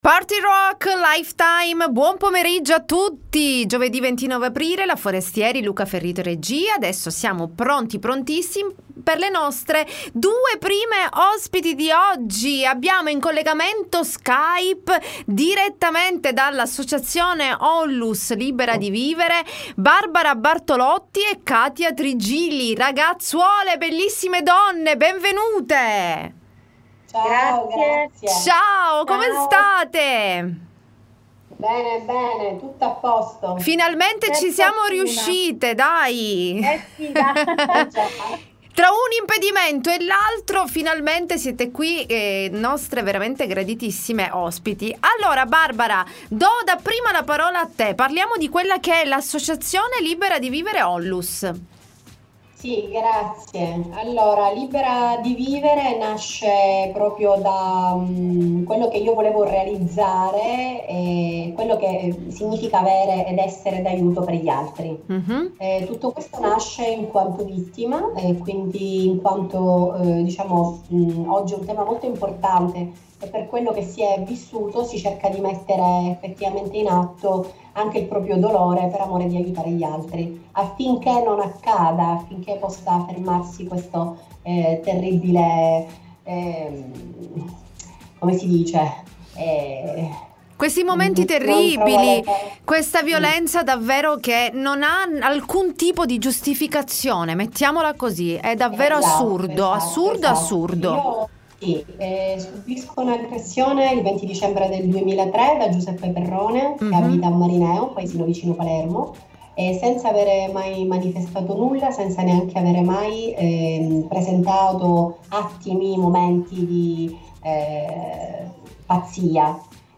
L.T. Intervista Associazione Libera di Vivere